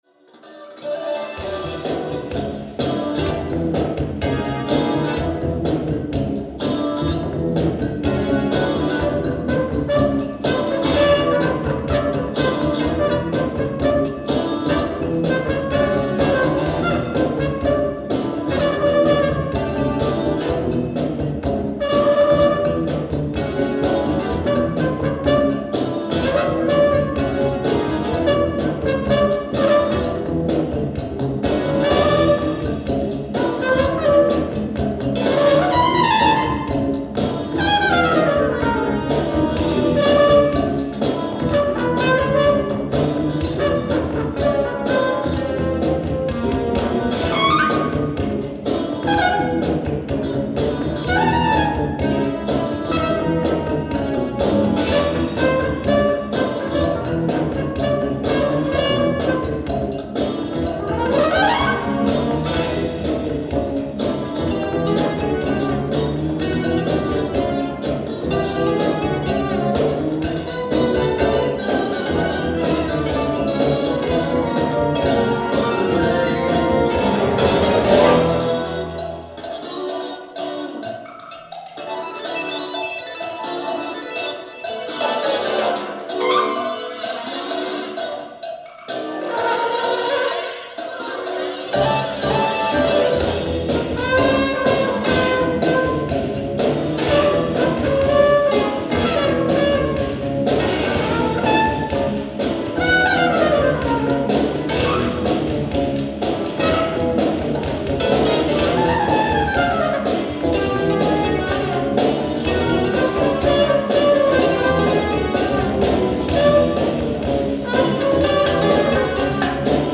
Live At Wembley Conference Center, London 11/16/1986